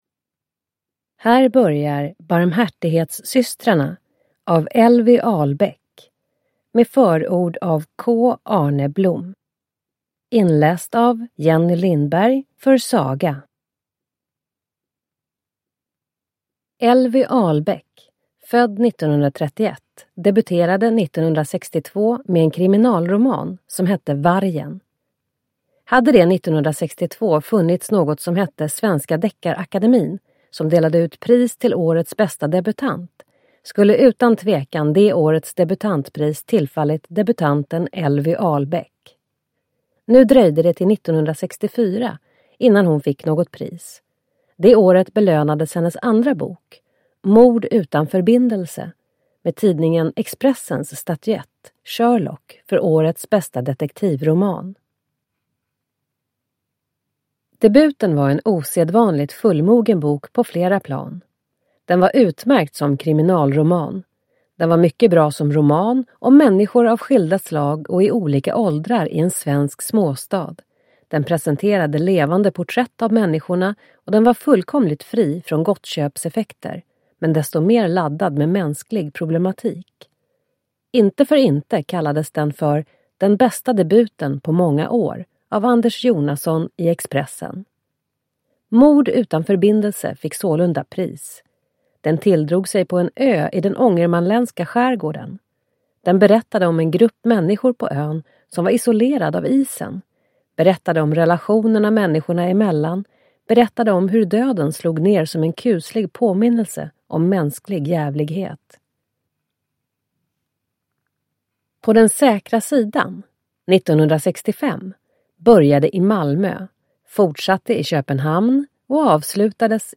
Barmhärtighetssystrarna – Ljudbok – Laddas ner